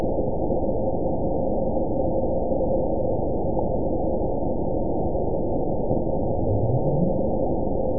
event 912069 date 03/17/22 time 14:41:10 GMT (3 years, 2 months ago) score 9.65 location TSS-AB03 detected by nrw target species NRW annotations +NRW Spectrogram: Frequency (kHz) vs. Time (s) audio not available .wav